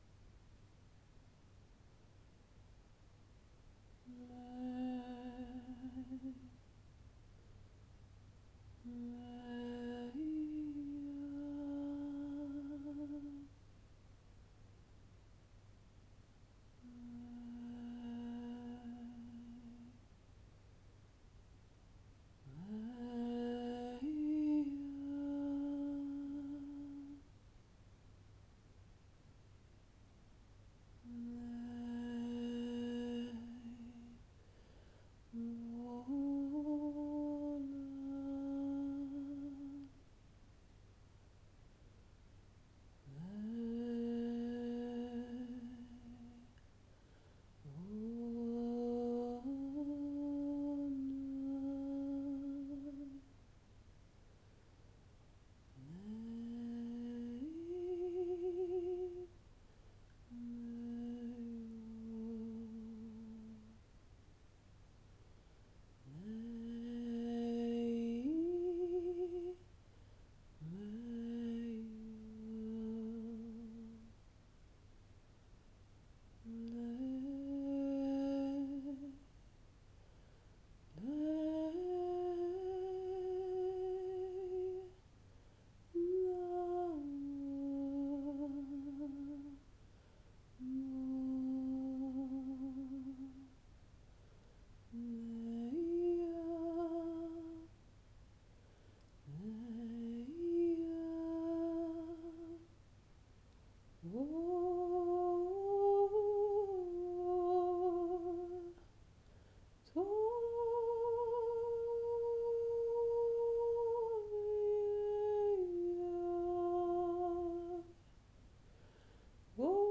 Nešponujte hlasitost, bývají tam hlasitější části, tak ať vám to pak neválcuje bubínky.
Pohlazeni-leciva-zvukomalba.wav